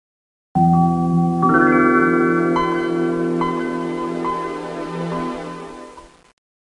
Microsoft Windows 95 Startup Sound Effect Free Download